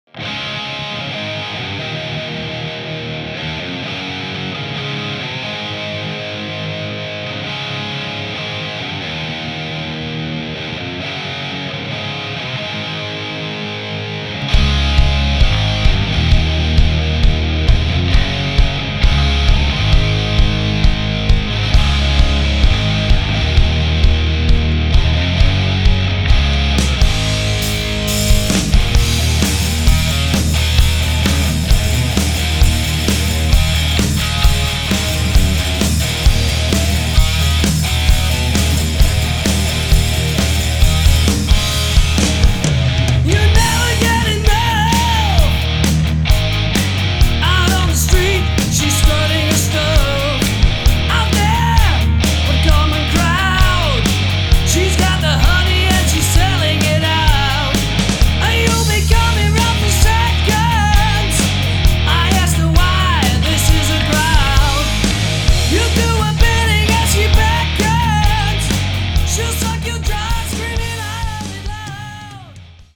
Hardrock
Zang
Gitaar
Drums